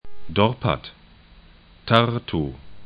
Dorpat 'dɔrpat Tartu 'tartu